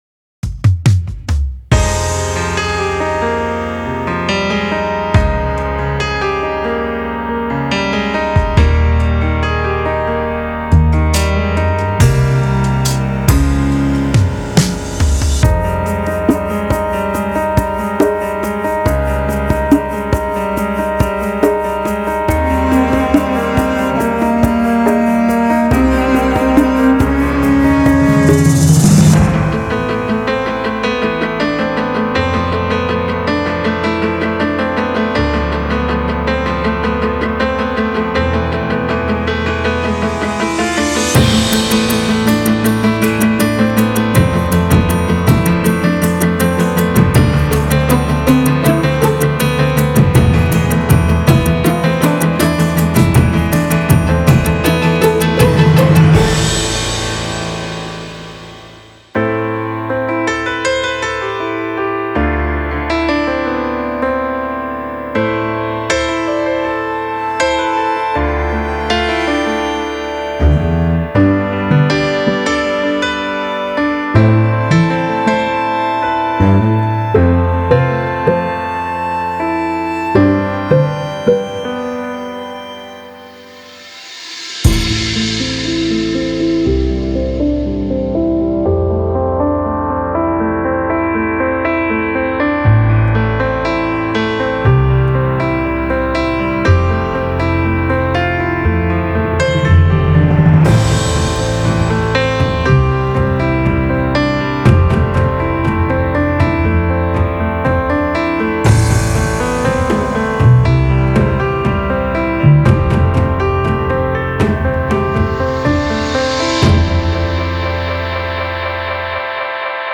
Genre:Cinematic
デモサウンドはコチラ↓
144 Piano Loops